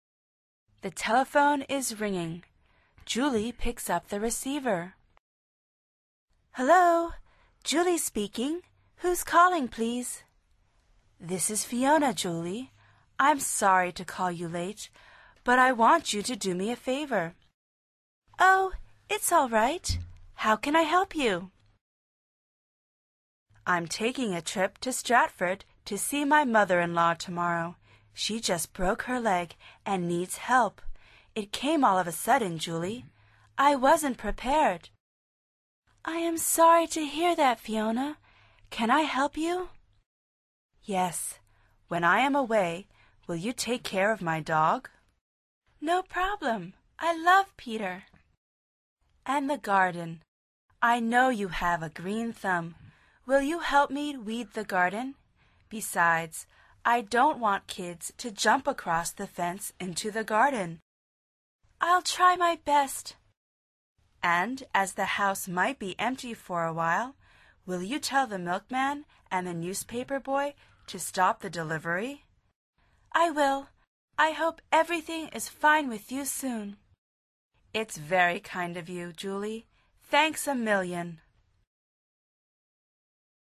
Pulsa las flechas de reproducción para escuchar el primer diálogo de esta lección. Al final repite el diálogo en voz alta tratando de imitar la entonación de los locutores.